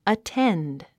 発音
əténd　アテェンド